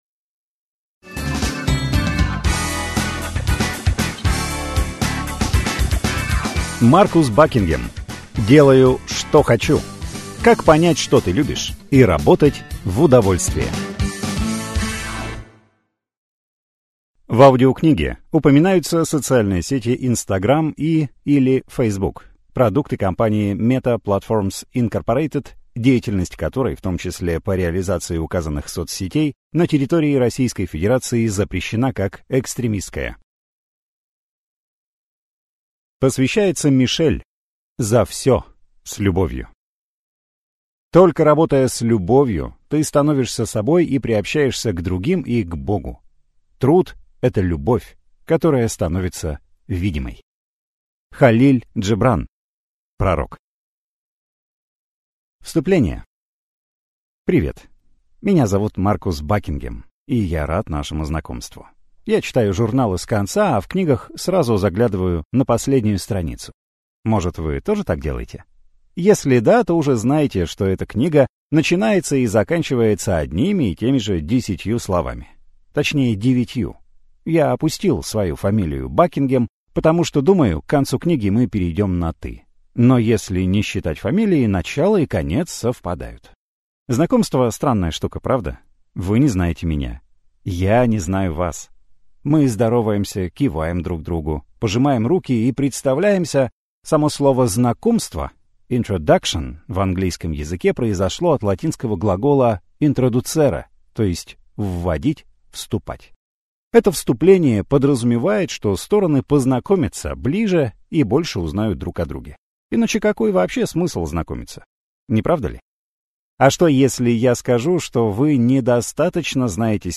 Аудиокнига Делаю, что хочу: Как понять, что ты любишь, и работать в удовольствие | Библиотека аудиокниг